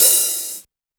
Open Hat
Original creative-commons licensed sounds for DJ's and music producers, recorded with high quality studio microphones.
crispy-open-hat-one-shot-d-sharp-key-29-mUz.wav